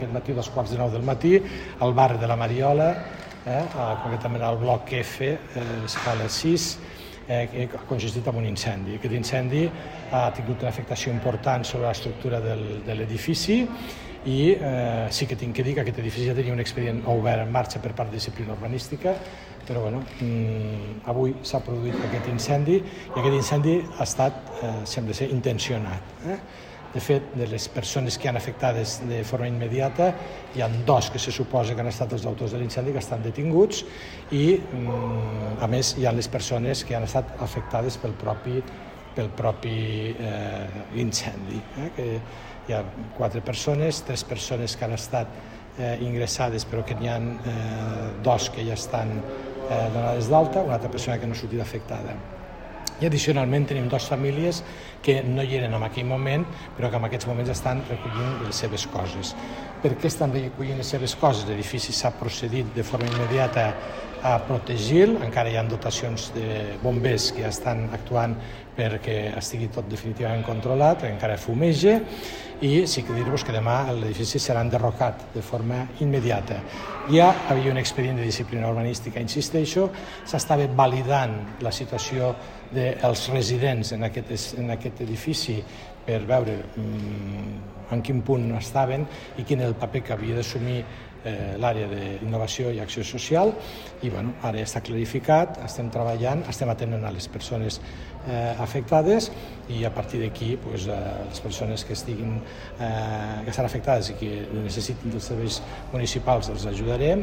Tall de veu del paer en cap, Fèlix Larrosa, sobre la transformació al barri de la Mariola (1.4 MB) ©Ajuntament de Lleida Tall de veu del paer en cap, Fèlix Larrosa, sobre l'incendi a la Mariola (1.6 MB) ©Ajuntament de Lleida